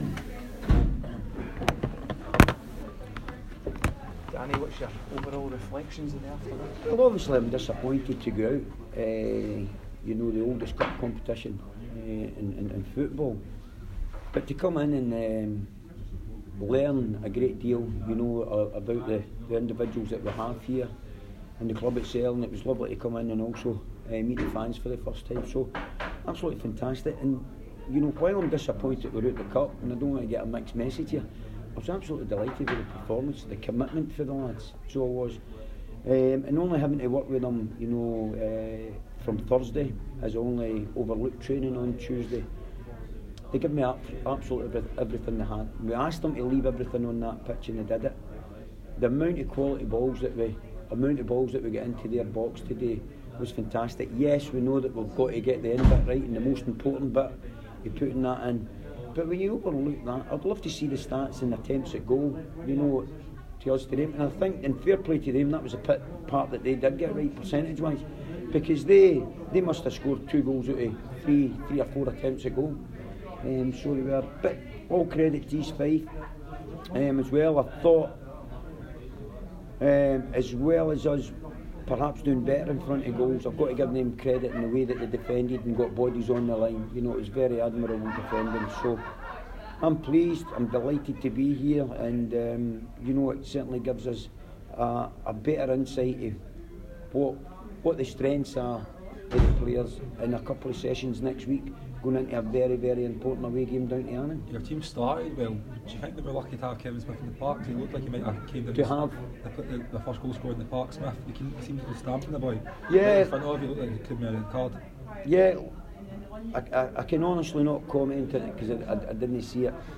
press conference after the William Hill Scottish Cup match.